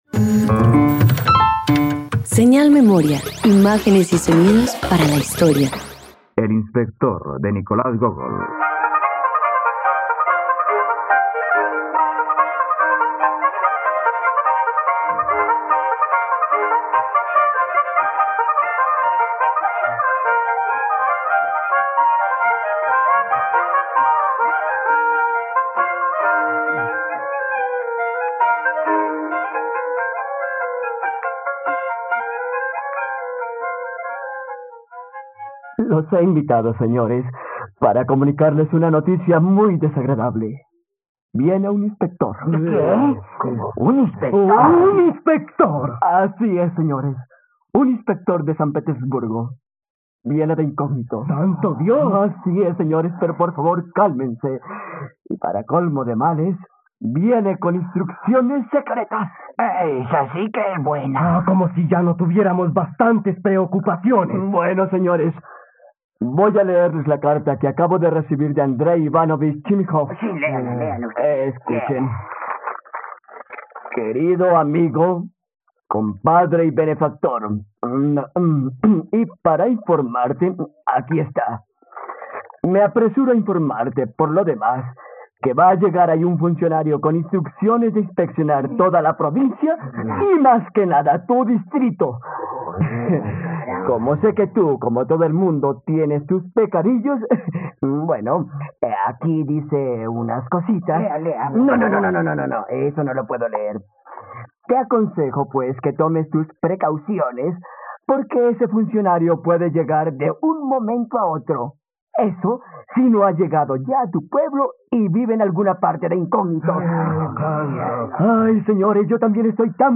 El inspector - Radioteatro dominical | RTVCPlay